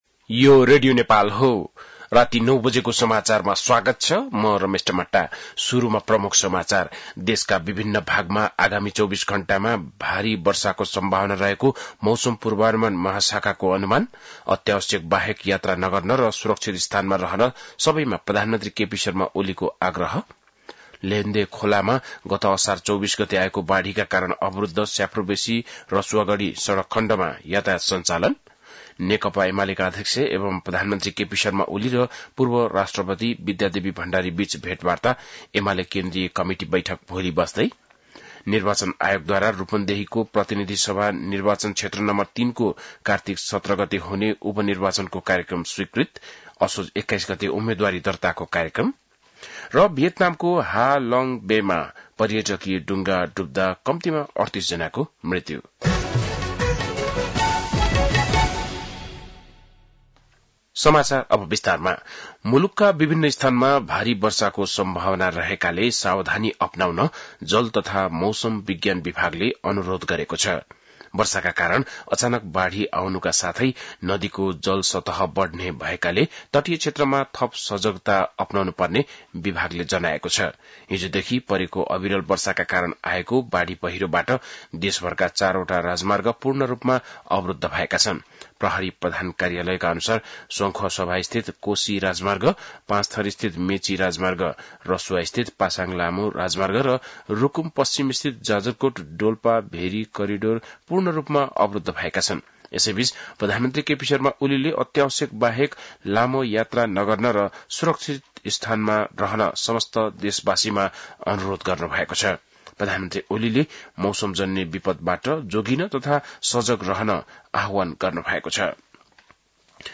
बेलुकी ९ बजेको नेपाली समाचार : ४ साउन , २०८२
9-pm-nepali-news-4-4.mp3